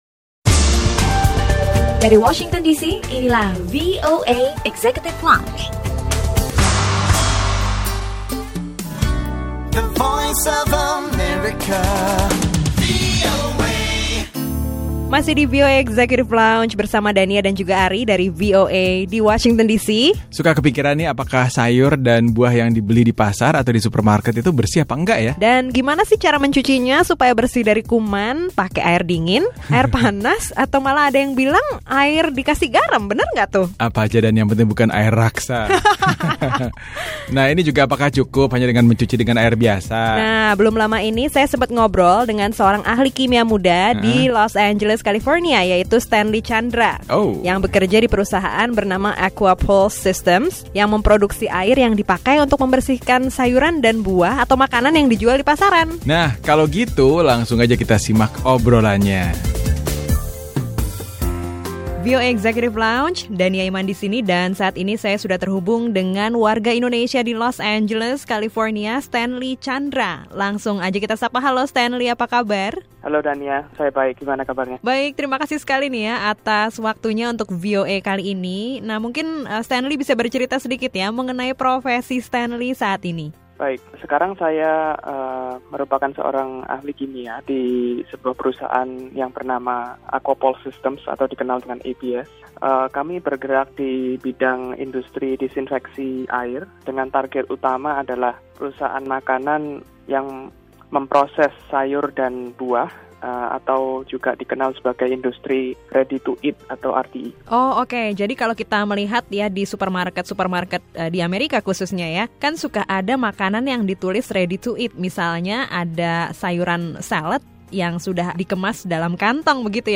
Obrolan bersama diaspora Indonesia di California yang berprofesi sebagai seorang ahli kimia seputar pengalamannya, sekaligus berbagi tips membersihkan buah dan sayuran yang baik bagi kesehatan.